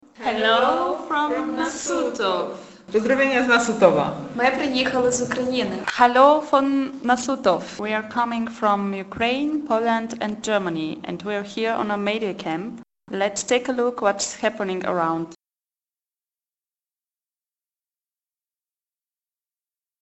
POZDROWIENIA Z NASUTOWA - POSŁUCHAJ
hello_from_nasutow.mp3